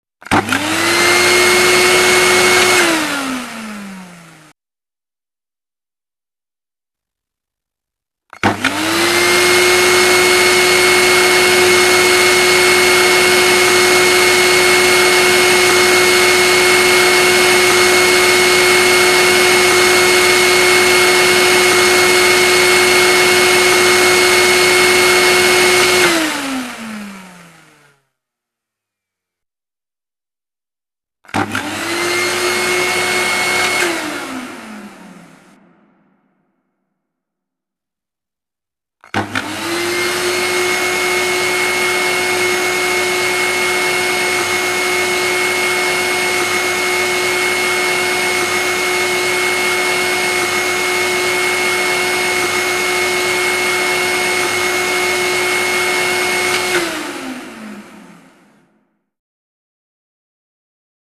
Звуки противные для соседей
Звук пылесоса на максимальной громкости